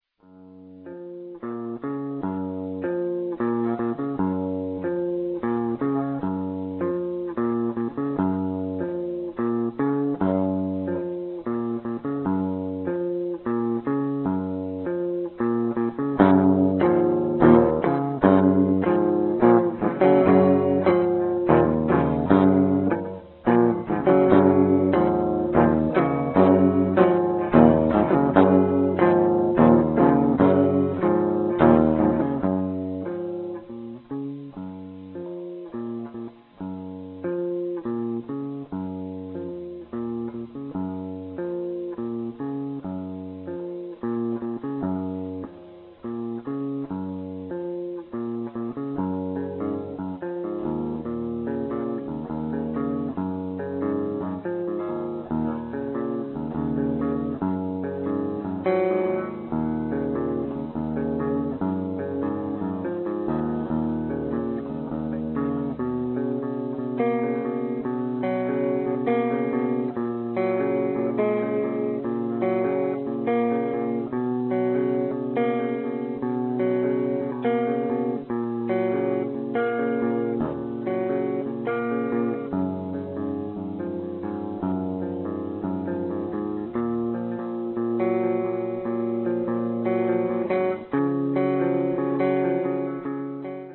A little collage of song's fragment from band's practices: